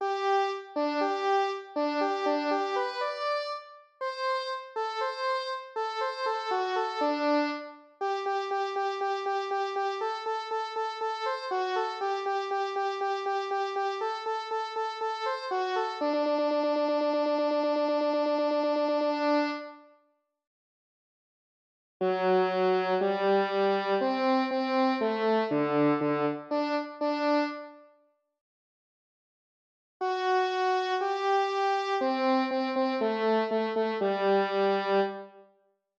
eine-kleine-cello-full.wav